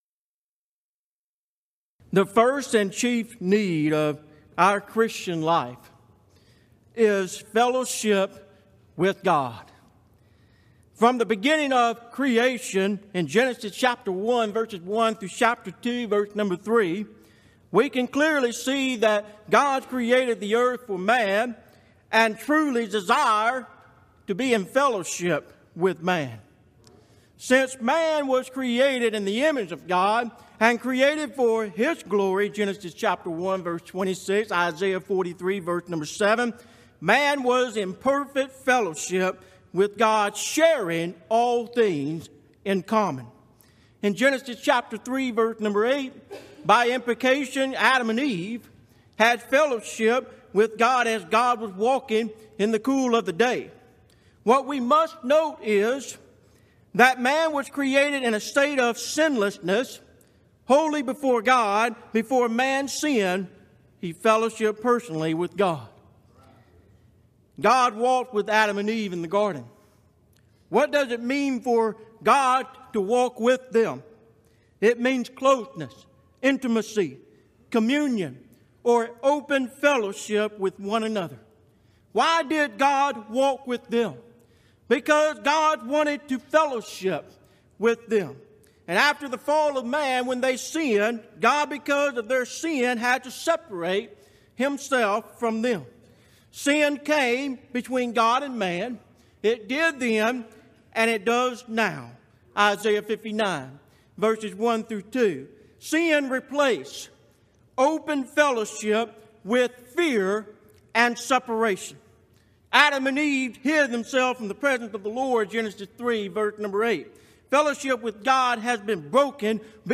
Event: 24th Annual Gulf Coast Lectures Theme/Title: Christian Fellowship
lecture